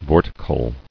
[vor·ti·cal]